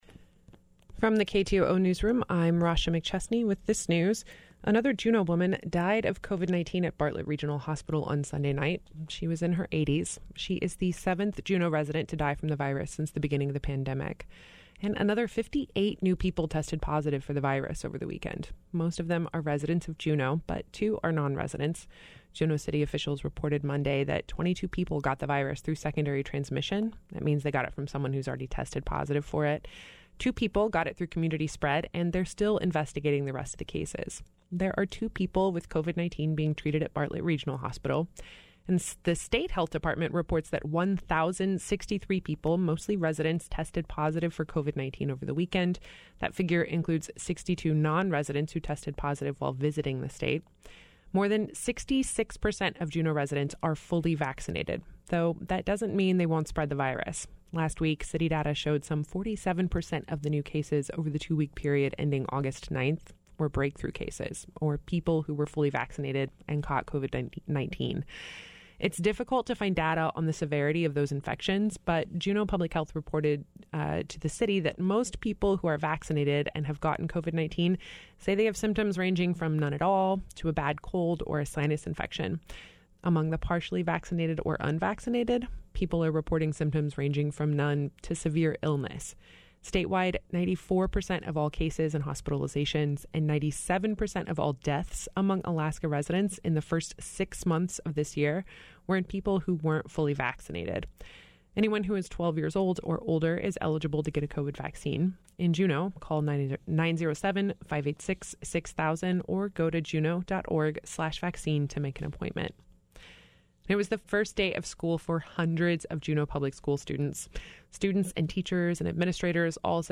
Newscast - Monday, Aug. 16, 2021